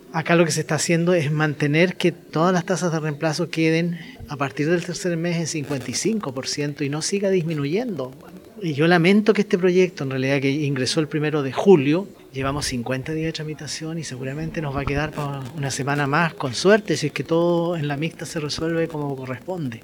El senador de Renovación Nacional, José García Ruminot, lamentó la demora en la aprobación de esta iniciativa que, en su opinión, es un beneficio simple y directo.